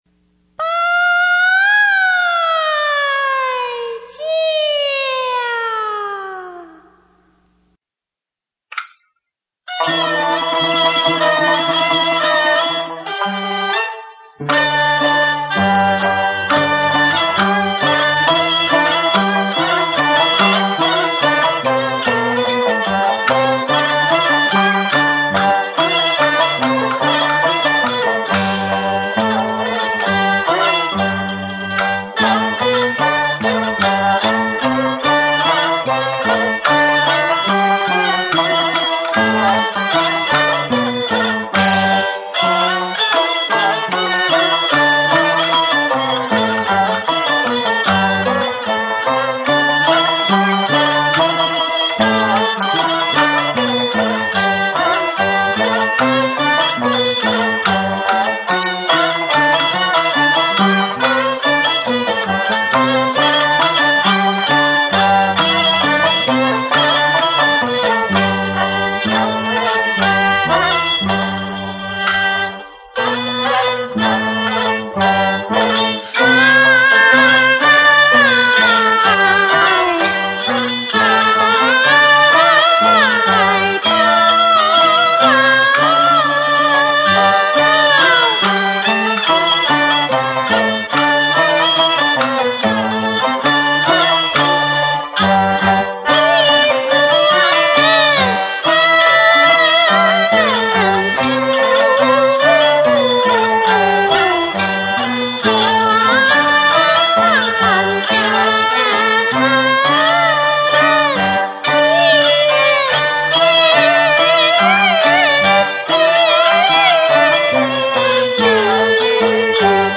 這段“四平調”代表了梅派的雍容華貴、典雅大方演唱風格，是工梅派的必修課。